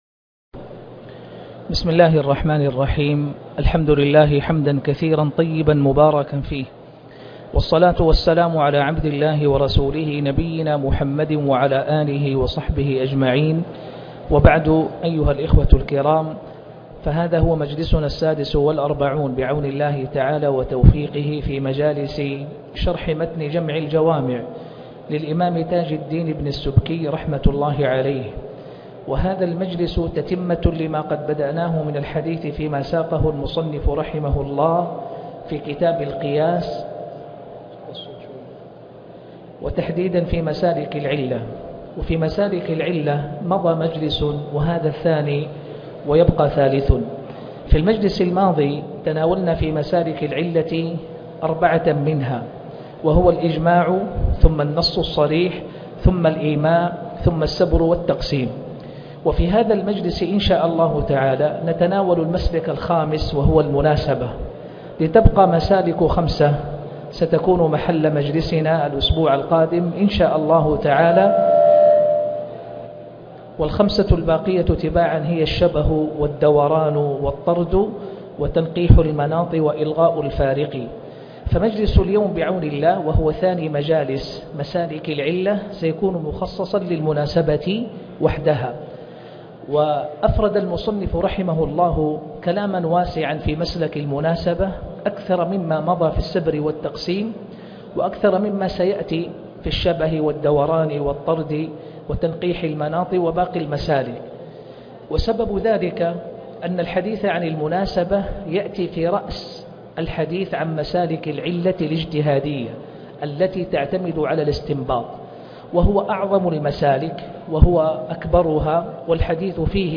شرح جمع الجوامع الدرس 46 - القياس _ مسالك العلة _ 2 المناسبة